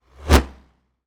bullet_flyby_deep_01.wav